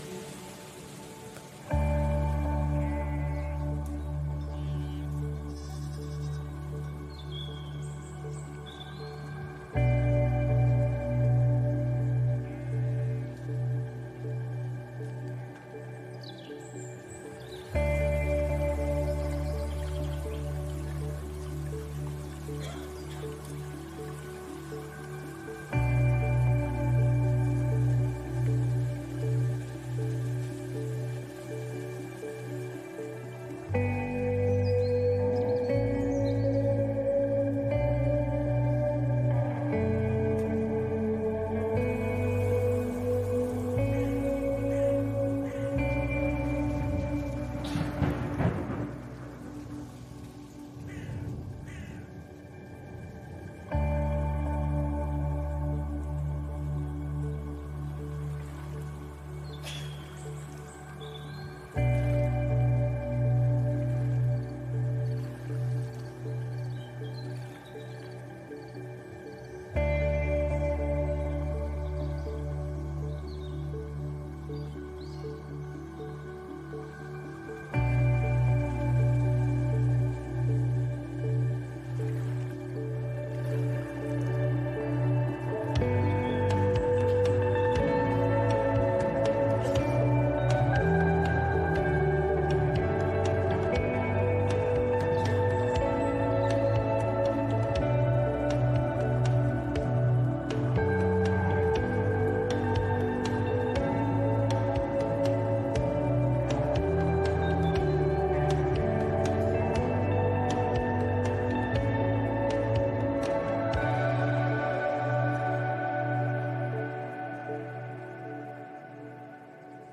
Sermons | New Hope Church